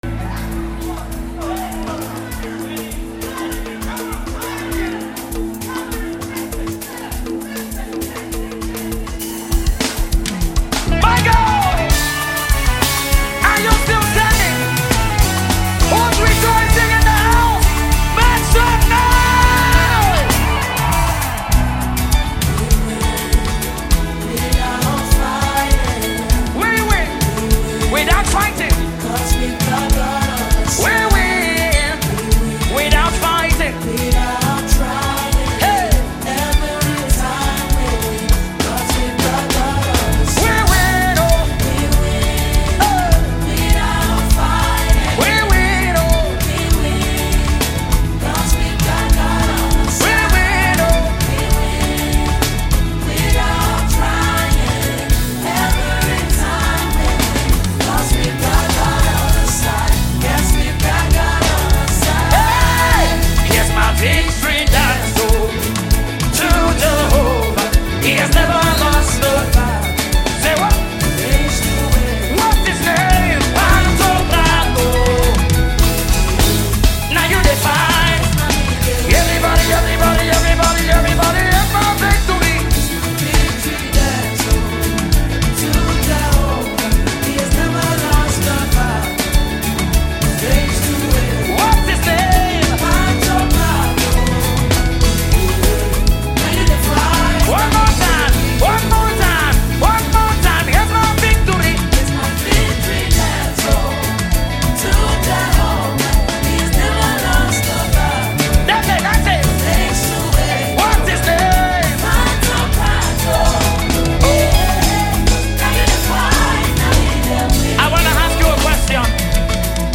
Home » Gospel Music » download mp3
Nigerian Gospel Music